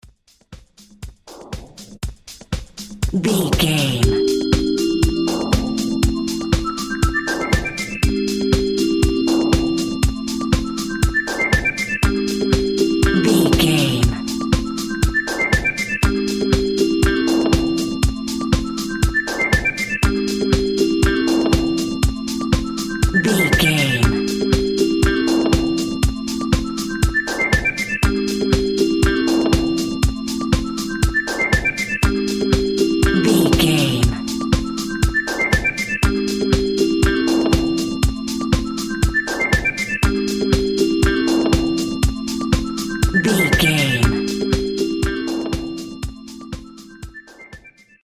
Diminished
electronic
dance
techno
synths
glitch
jazz drums
jazz bass